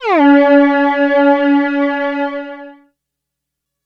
Gliss 02.wav